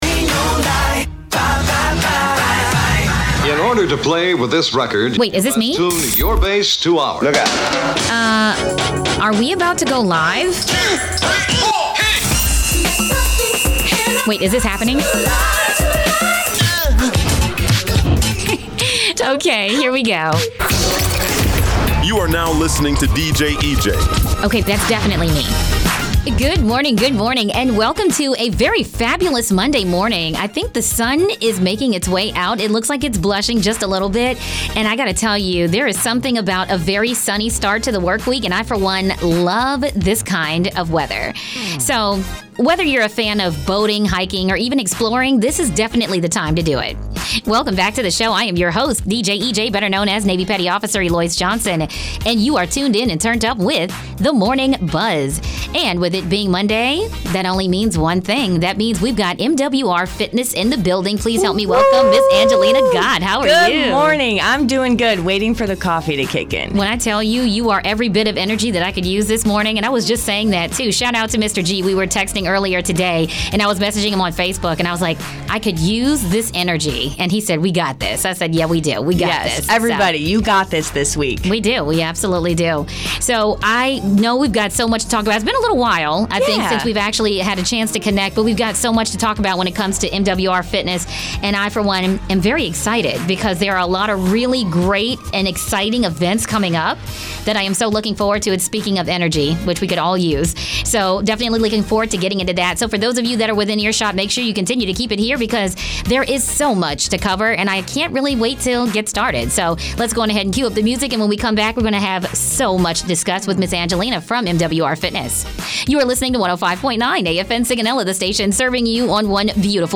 An interview
a recreation specialist, to discuss upcoming events within Morale, Welfare and Recreation (MWR) Sigonella.